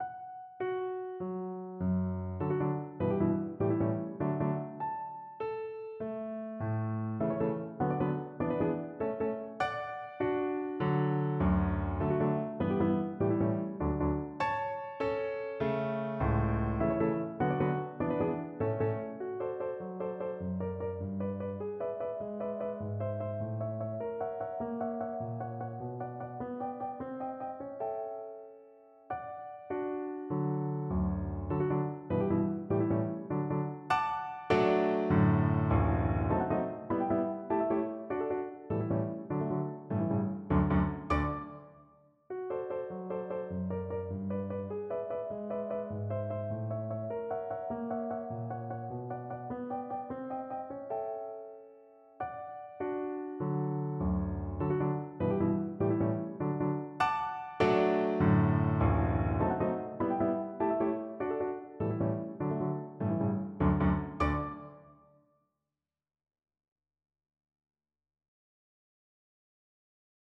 Скерцо из Сонаты для фортепиано № 15 Людвига ван Бетховена ре мажор, соч. 28 (так называемая «Пасторальная» соната) демонстрирует ряд этих процессов в малом масштабе.